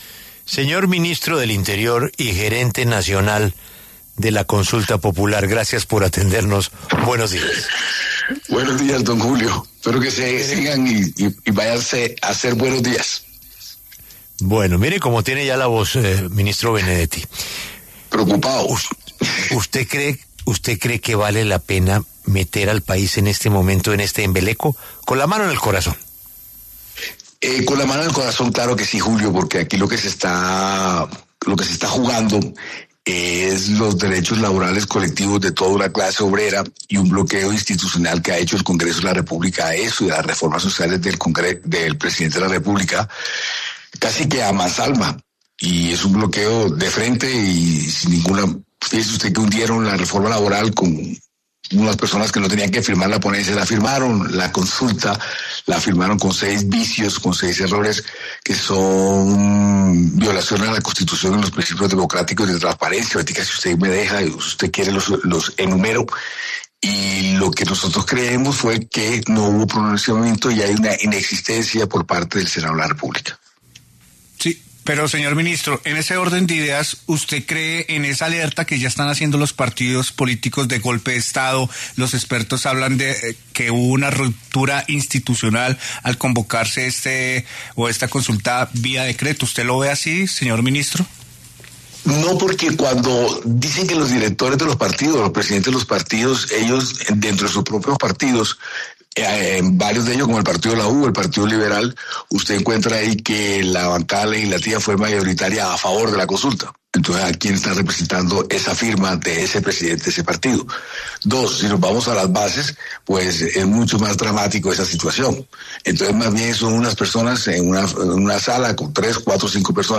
El ministro del Interior, Armando Benedetti, habló en La W sobre la decisión del Gobierno de decretar la consulta popular.